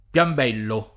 Piambello [ p L amb $ llo ] top. m. (Lomb.)